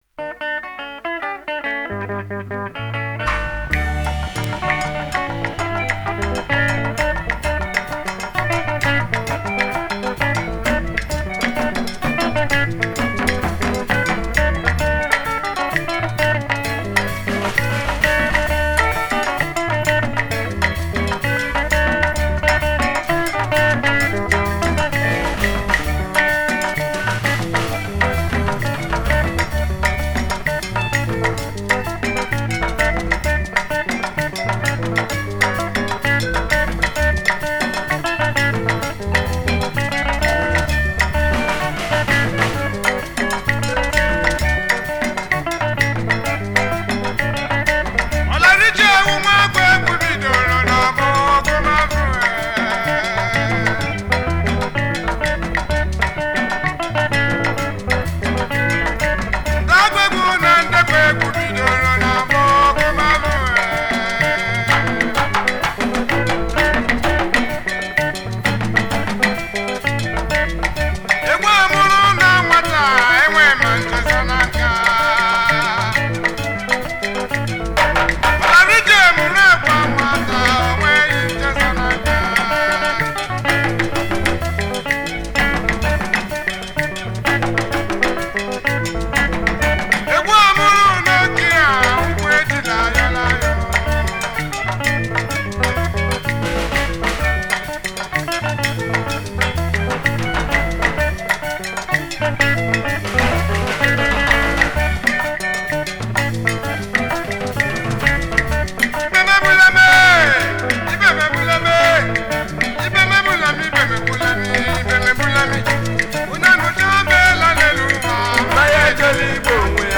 September 9, 2024 admin Highlife Music, Music 0
Igbo highlife